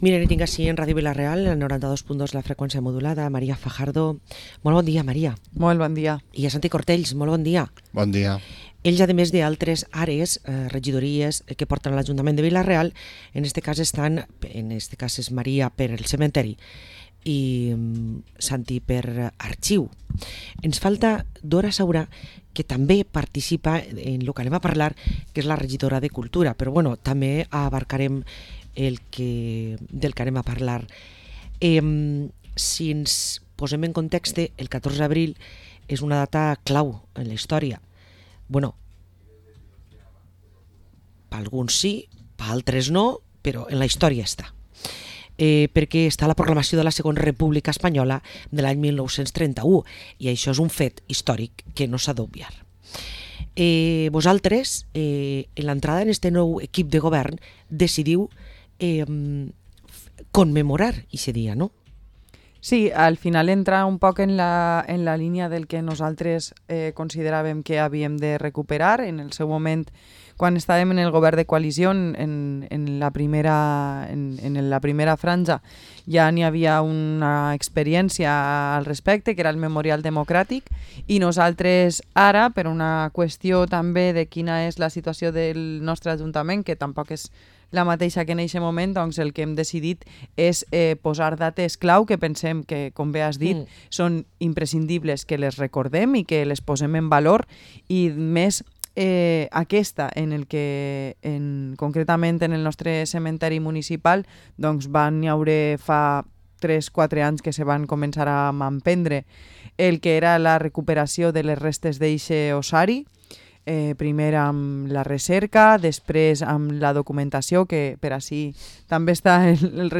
Parlem amb Santi Cortells i María Fajardo, regidors a l´Ajuntament de Vila-real